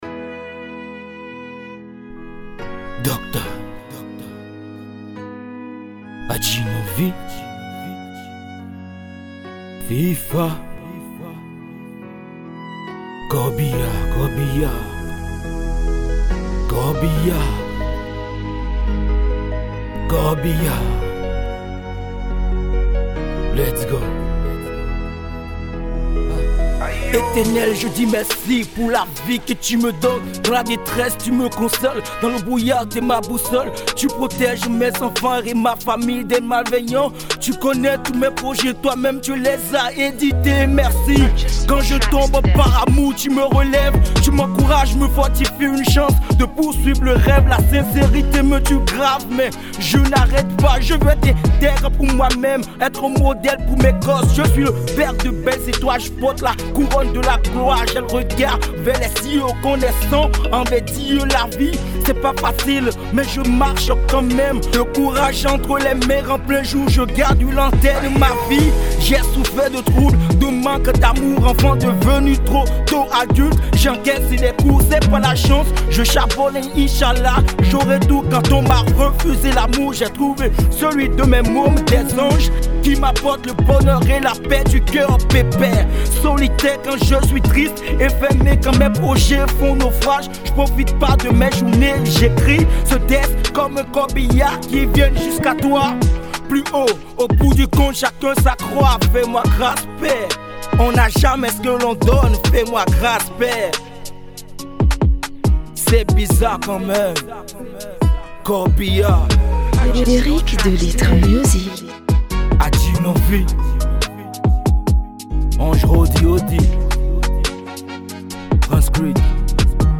Urban